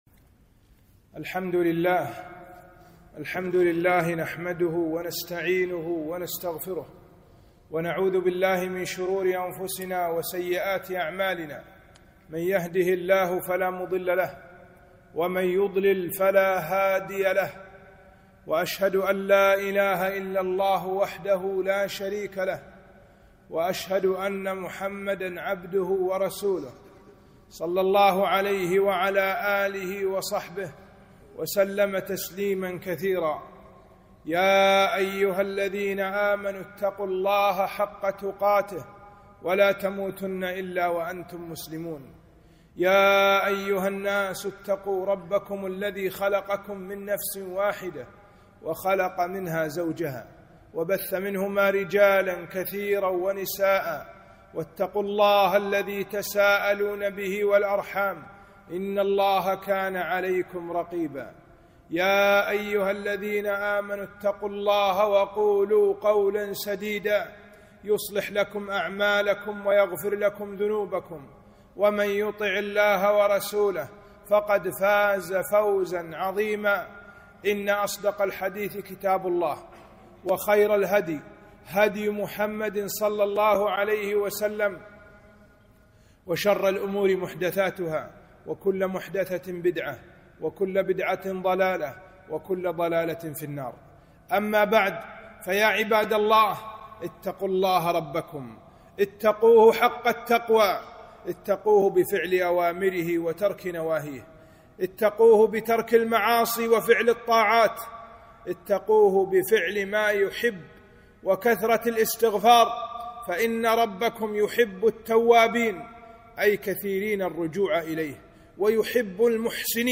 خطبة - ( فقلت استغفروا ربكم إنه كان غفار يرسل السماء عليكم مدرارا)